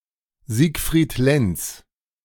Siegfried Lenz (German: [ˈziːkfʁiːt ˈlɛnts]
De-Siegfried_Lenz.ogg.mp3